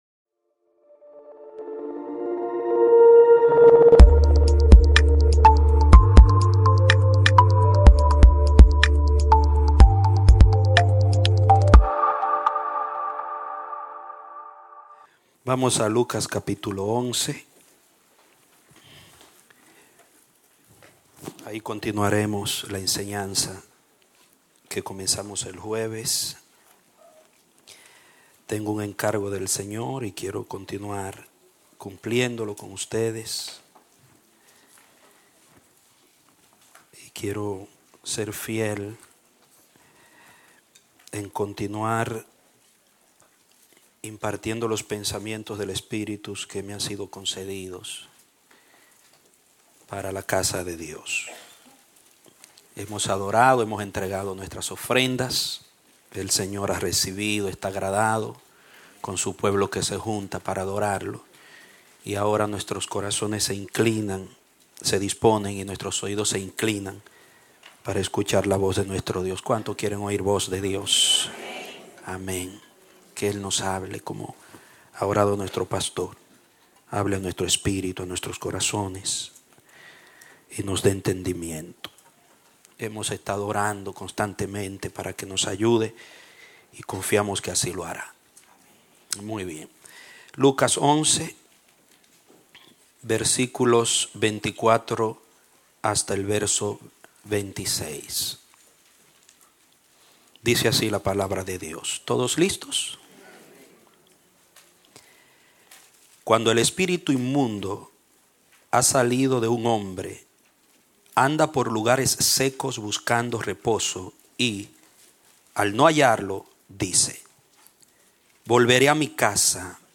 Un mensaje de la serie "Metástasis."